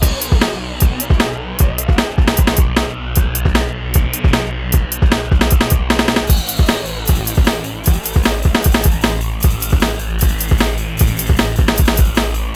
33 Drumbeat Goes-a.wav